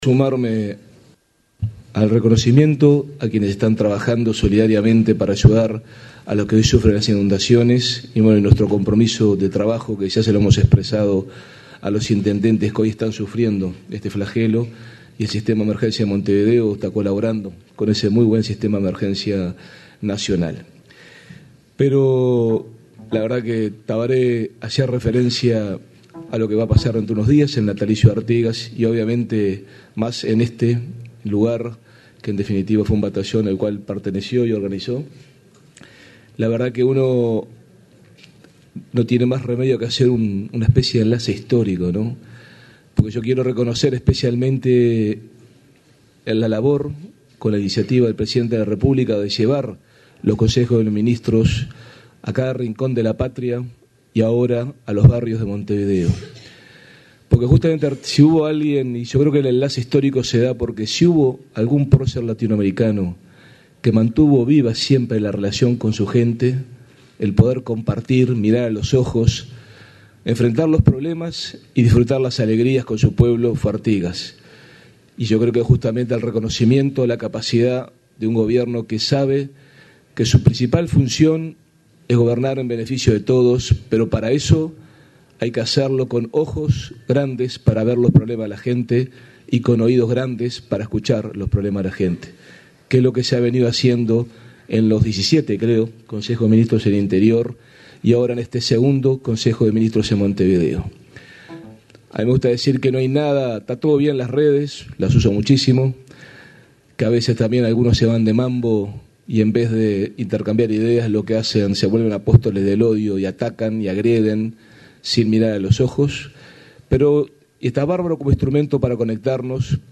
“La principal función de quien gobierna es hacerlo en beneficio de todos, con ojos y oídos grandes para ver y escuchar los problemas de la gente”, afirmó el intendente de Montevideo, Daniel Martínez, en la apertura del segundo Consejo de Ministros abierto en la capital. Reafirmó el compromiso de su intendencia con los intendentes de los departamentos afectados por las inundaciones.